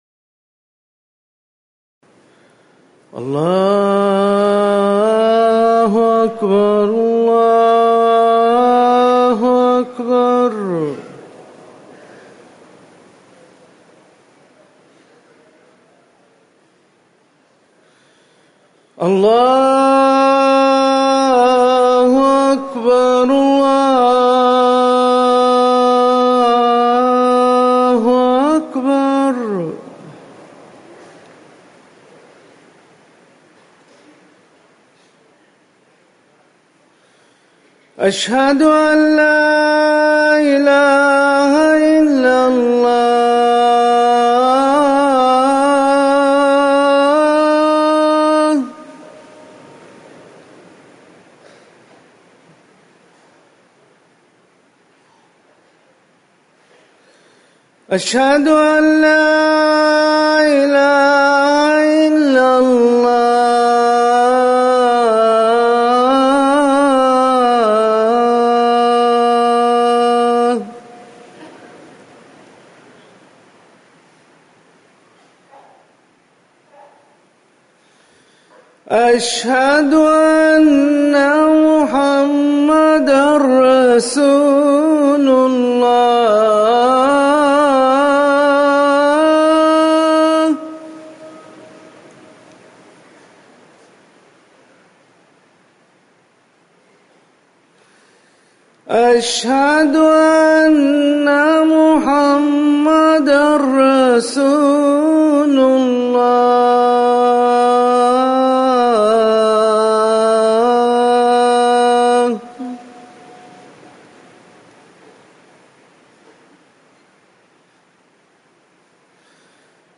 أذان الجمعة الأول
تاريخ النشر ٥ صفر ١٤٤١ هـ المكان: المسجد النبوي الشيخ